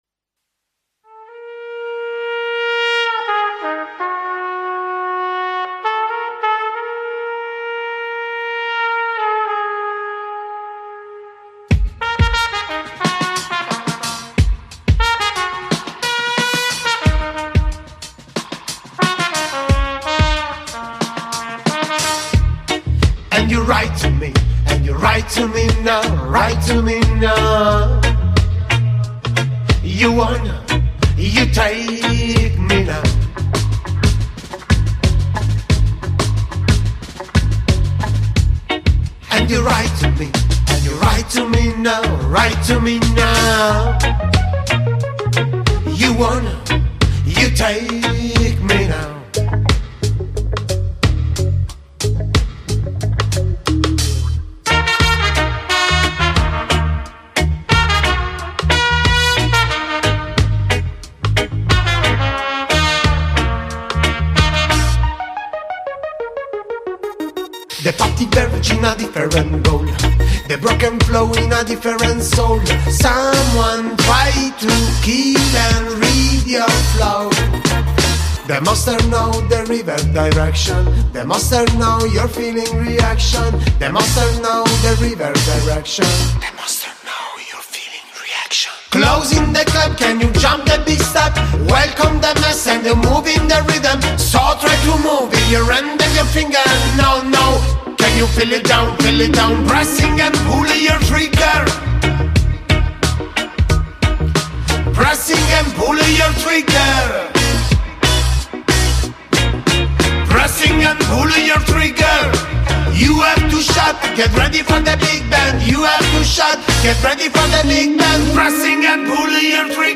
Artista-a-la-Vista-Intervista-Koreni-Foundation.mp3